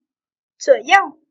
zuǒyòu - trủa giâu Trái phải